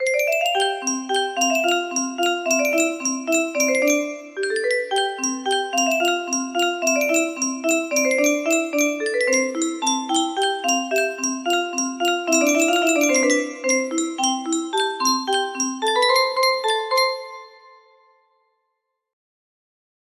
Worlds smallest ferris wheel music box melody